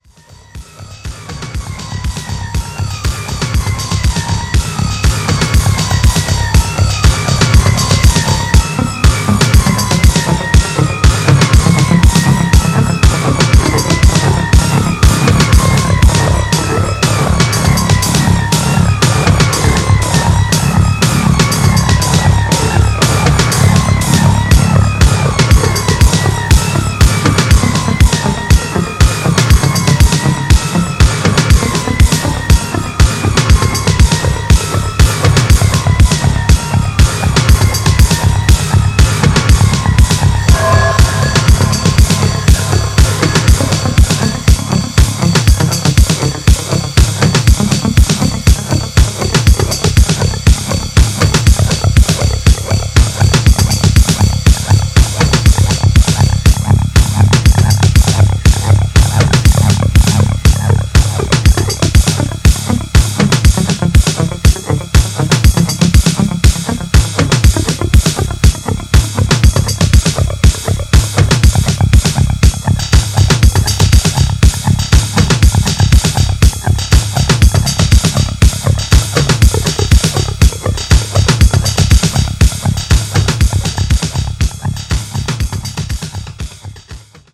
怒涛のトライバル・ドラムに錐揉みシンセ、ガビガビのシーケンスが極めて凶悪な